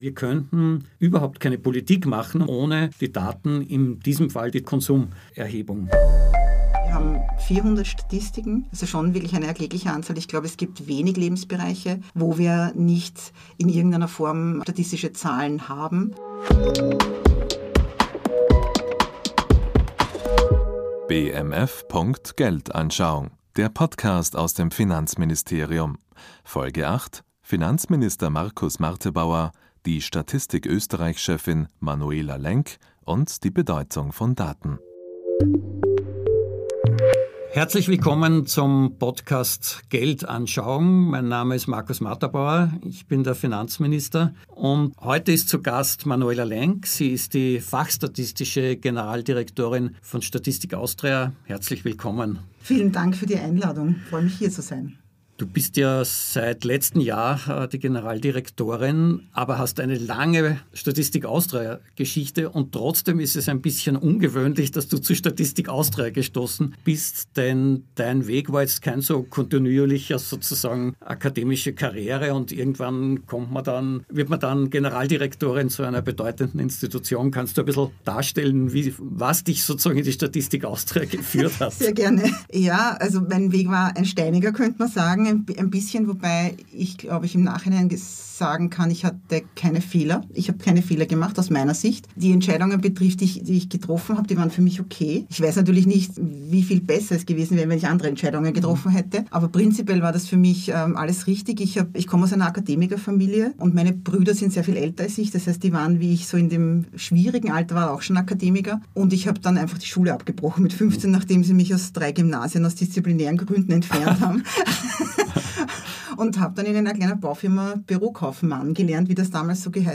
Ein Gespräch über die Bedeutung der Unabhängigkeit der Statistik Austria, politische Entscheidungen und die Wichtigkeit von Daten.